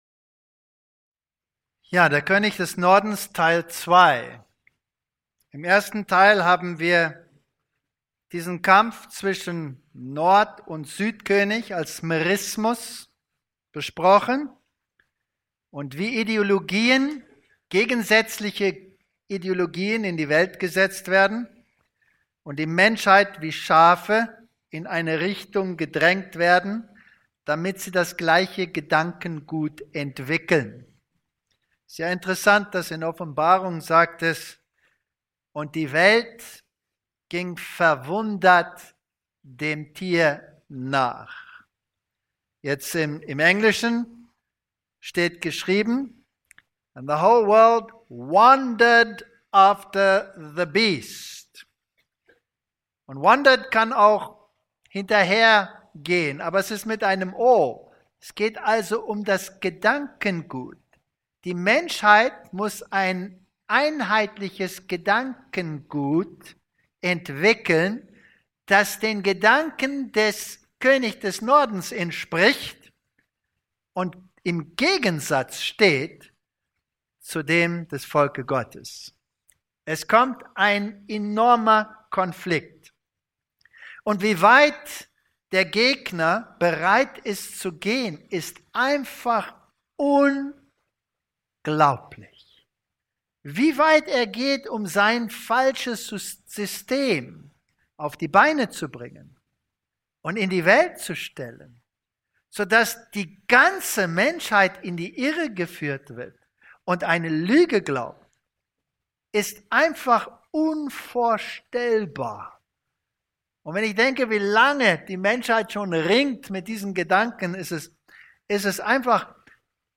Dieser Vortrag widmet sich den Irrlehren über den Antichristus sowie die Rolle des heutigen Israel in der biblischen Prophetie.